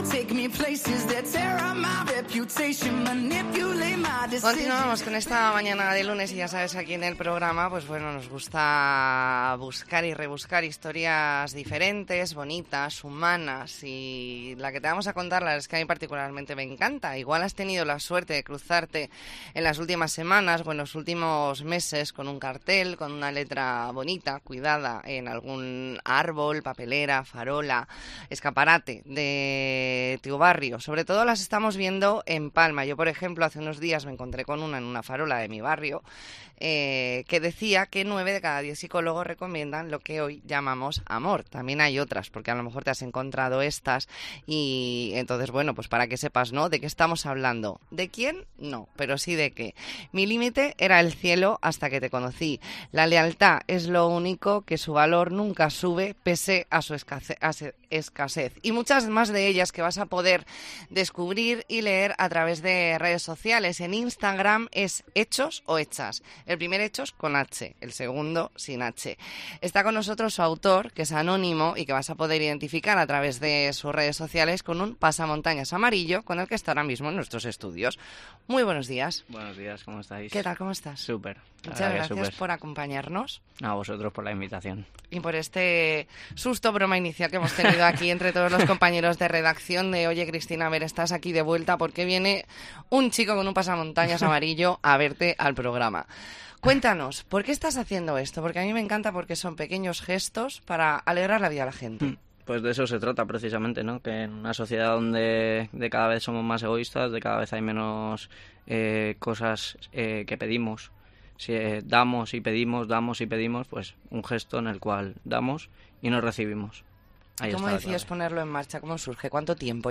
Entrevista en La Mañana en COPE Más Mallorca, lunes 25 de septiembre de 2023.